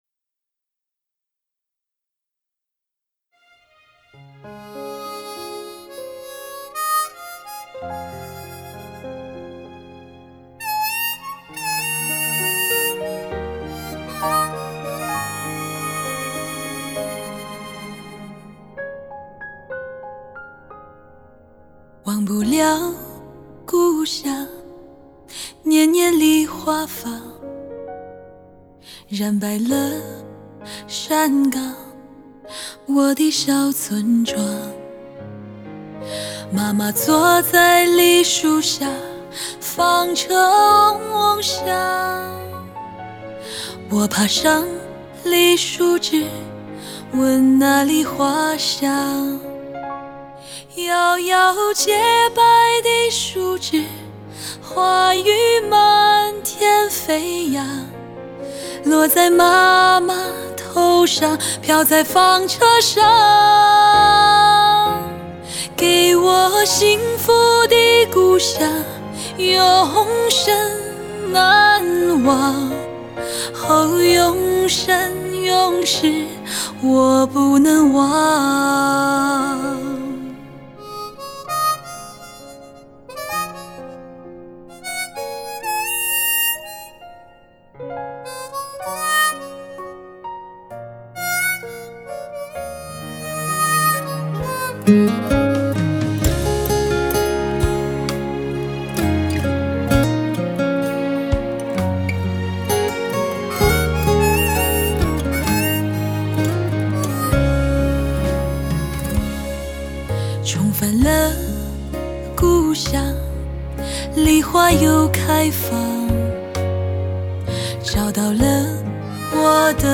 句每一首曲子，静静私语都能温暖你的心